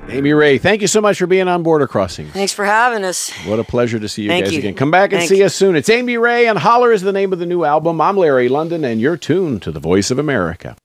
(recorded from a webcast)
08. interview (0:10)